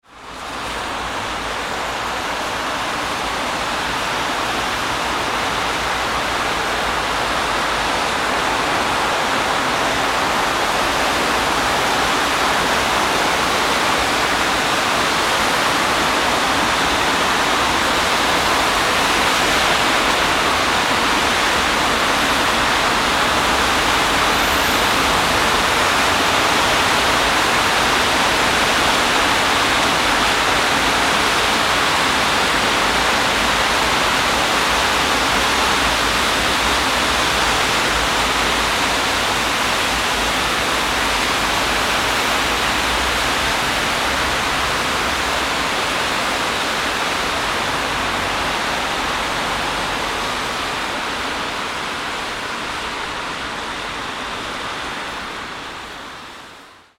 Wet-road-car-sound-effect.mp3